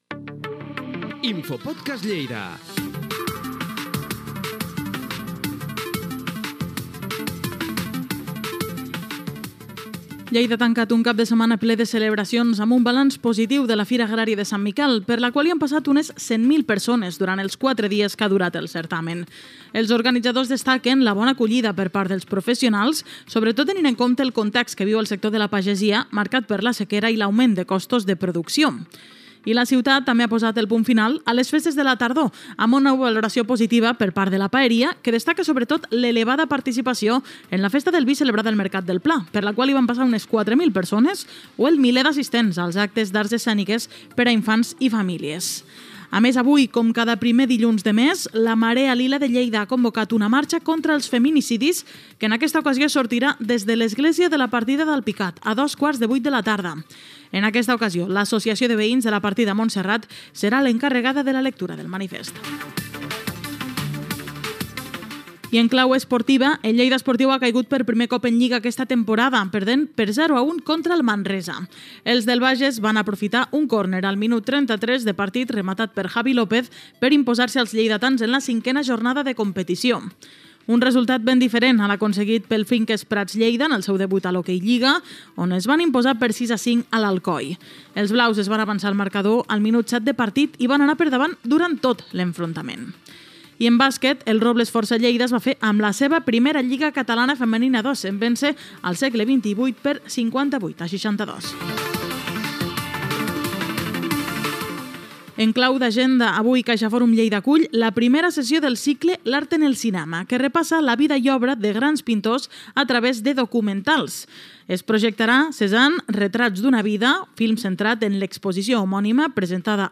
Indicatiu del programa, la fira de Sant Miquel i les festes de la tardor, esports, actes culturals i indicatiu
Informatiu